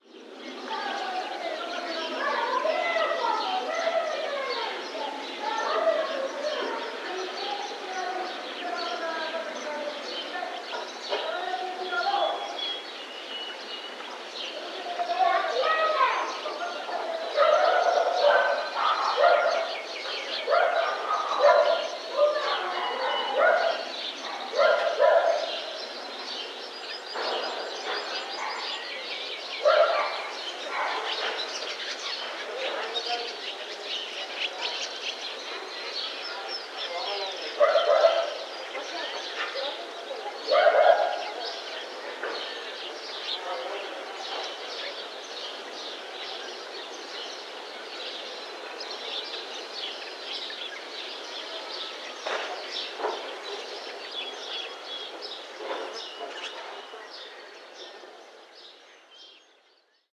Ambiente de pueblo en verano
bullicio
Sonidos: Animales
Sonidos: Gente
Sonidos: Rural